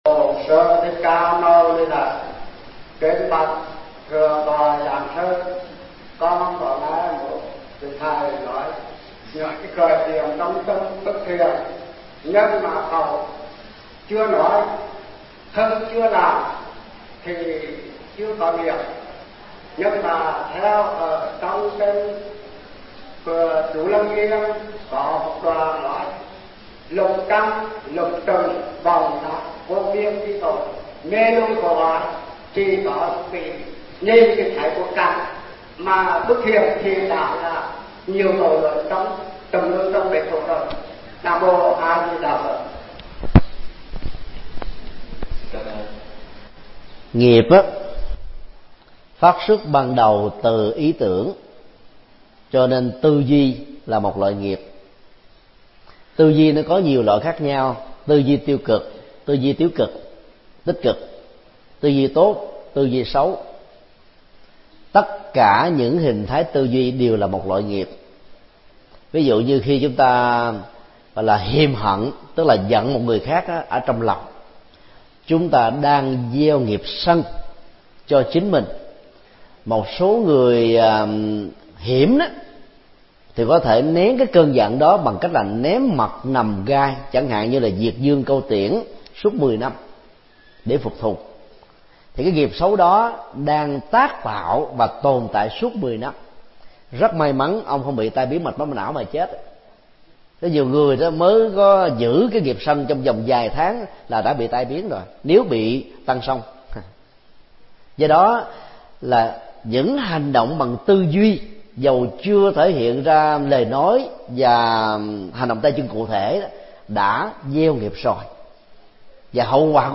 Vấn đáp: Tư duy và gieo nghiệp